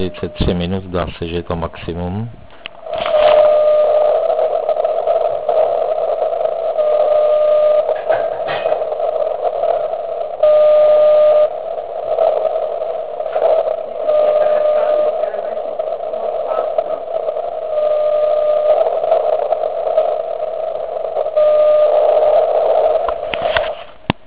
Vysílal zkušebně na 3536.1 KHz.
V Liberci:
Maximum signálu je tedy někde kolem 2030 SELC.